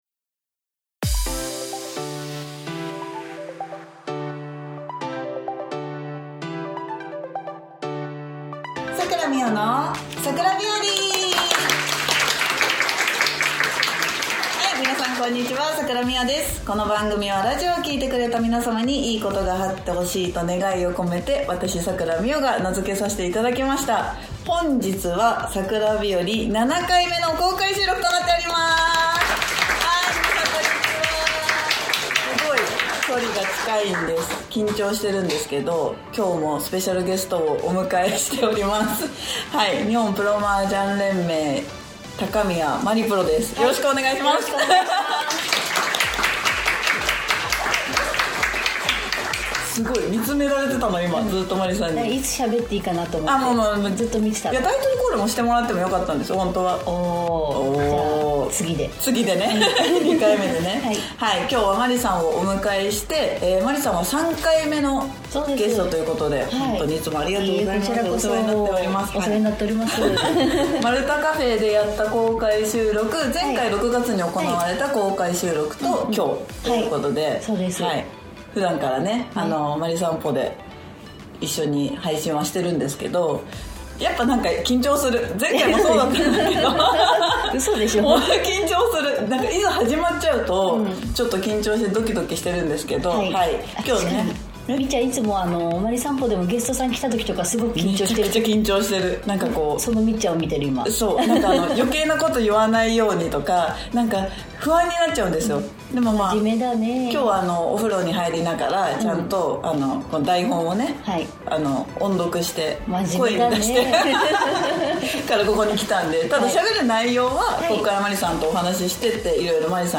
およそ8ヶ月ぶりの公開収録です！